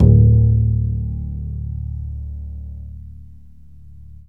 DBL BASS EN1.wav